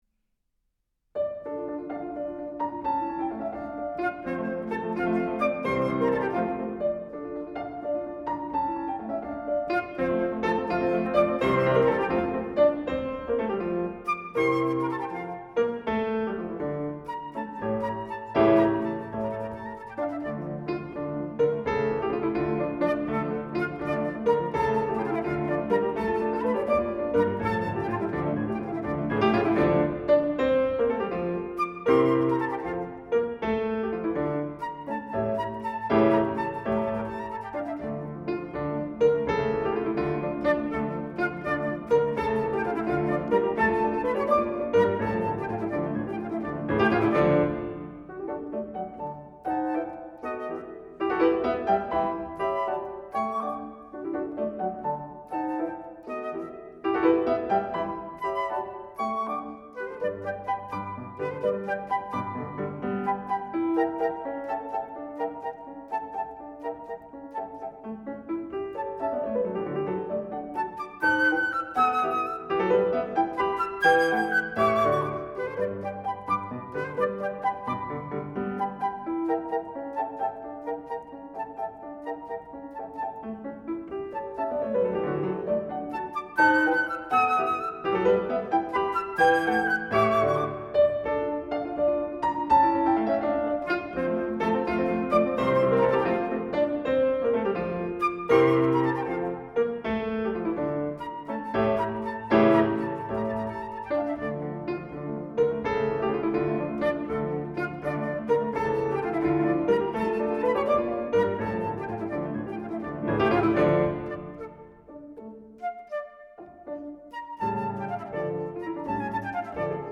長笛
鋼琴
古典音樂